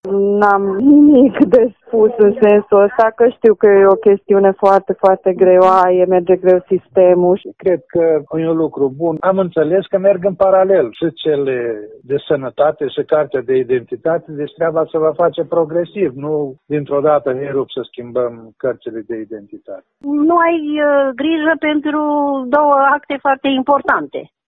Mureșenii salută propunerea și apreciază mai ales partea referitoare la comasarea cărții de identitate cu cardul de sănătate: